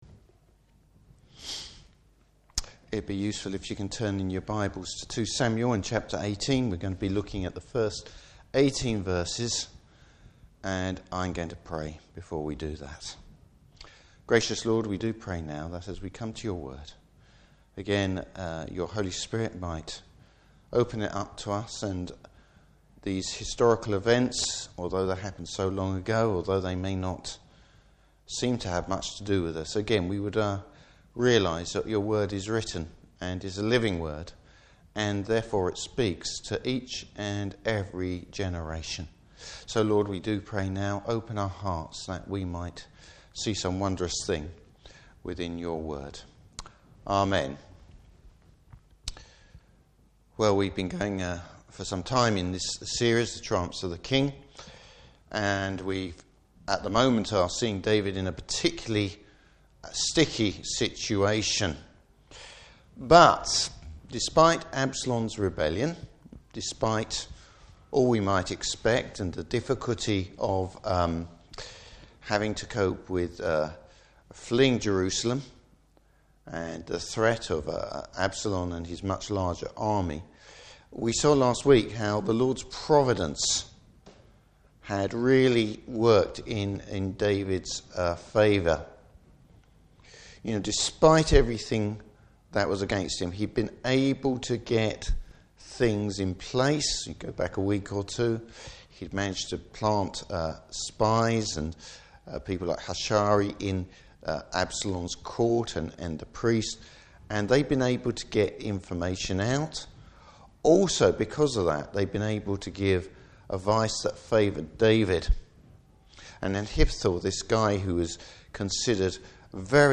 Passage: 2 Samuel 18:1-18. Service Type: Evening Service Absalom’s tragic end!